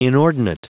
Prononciation du mot inordinate en anglais (fichier audio)
Prononciation du mot : inordinate